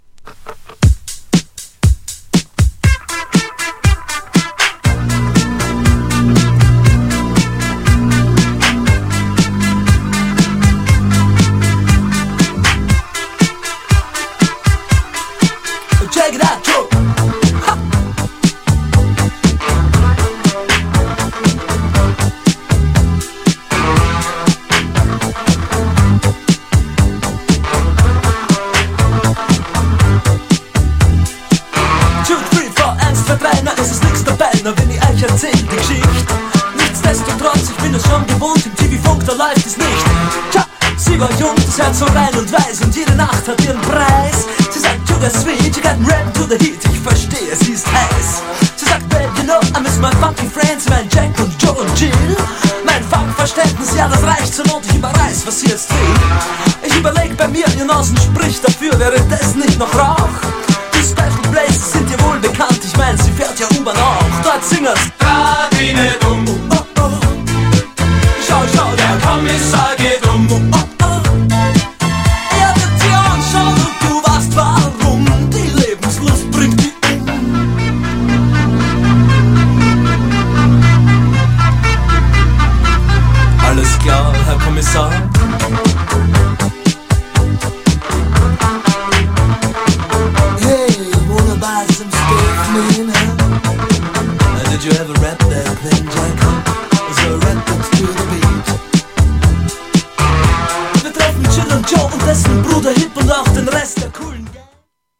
GENRE Dance Classic
BPM 151〜155BPM